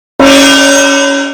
1 channel
Title=gong02